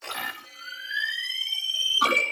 SFX_Robot Start Up_01.wav